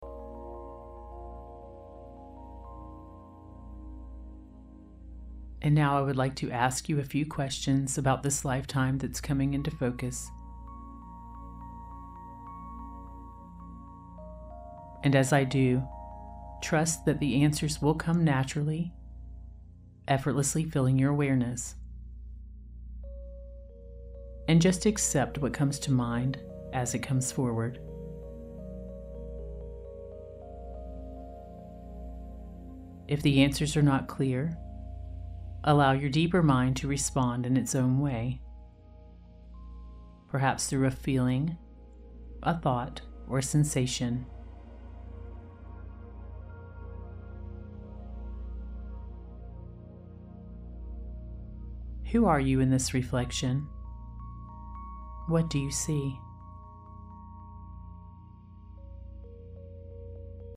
Through carefully designed hypnotic suggestions, immersive 3D soundscapes, and alpha wave entrainment, this session creates the perfect state for past life recall, helping you access the knowledge, lessons, and insights that may still influence you today.
The embedded alpha wave track keeps your mind in the perfect balance—deeply relaxed, yet aware—so you can access memories while maintaining clarity and recall.
Immersive 3D Binaural Sound & Hypnotic Induction:
Designed with minimal spatial audio and light hypnotic layering, this experience enhances relaxation and deepens your connection to past life recall in an effortless, natural way.